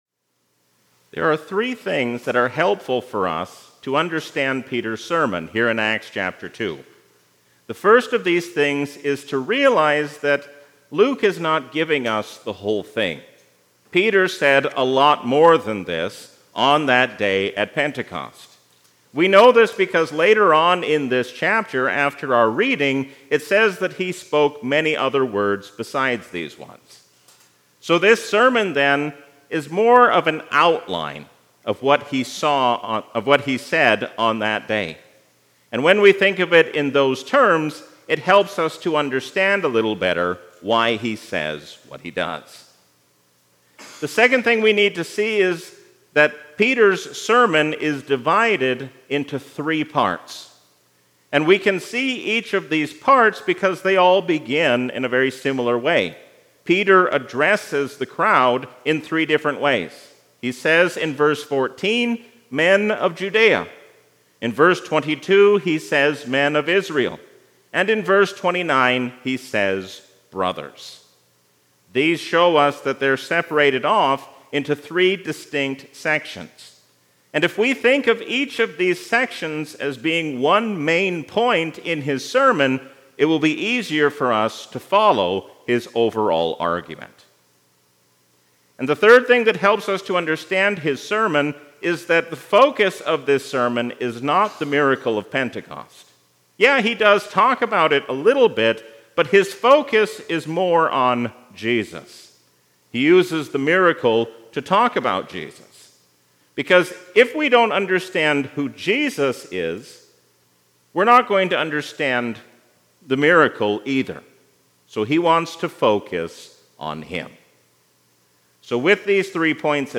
A sermon from the season "Trinity 2024." Peter proves that Jesus is the long-promised Lord and Christ and shows us what it means to proclaim Him to the world.